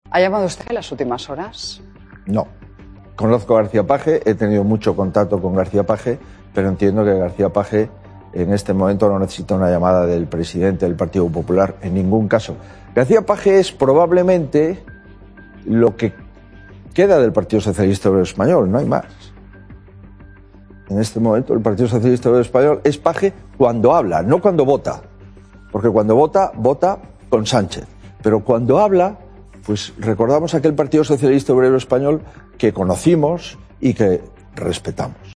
Intervención de Alberto Núñez Feijóo hablando sobre García Page